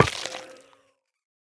Minecraft Version Minecraft Version snapshot Latest Release | Latest Snapshot snapshot / assets / minecraft / sounds / block / sculk_catalyst / break5.ogg Compare With Compare With Latest Release | Latest Snapshot
break5.ogg